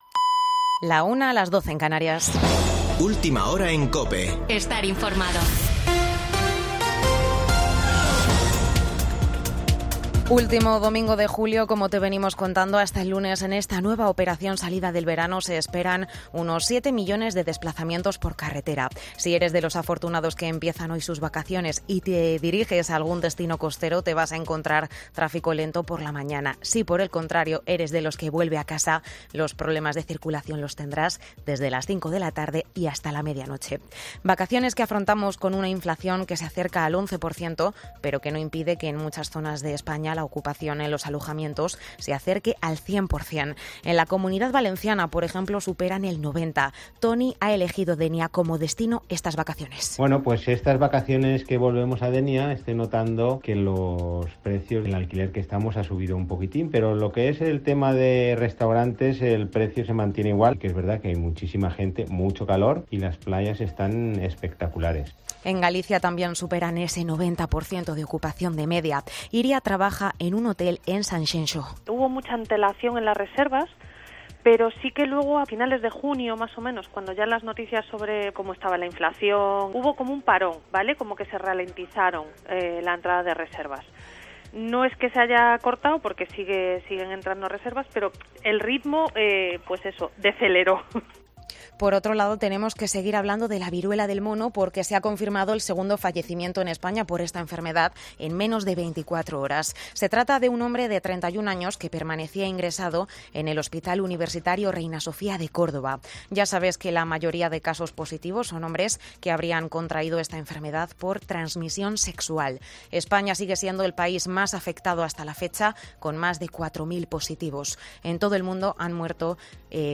Boletín de noticias de COPE del 31 de julio de 2022 a las 01:00 horas